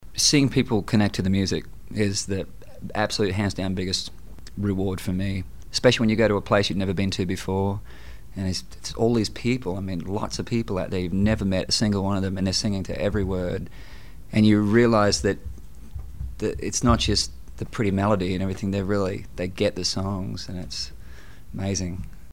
Audio / KEITH URBAN TALKS ABOUT PERFORMING FOR FANS.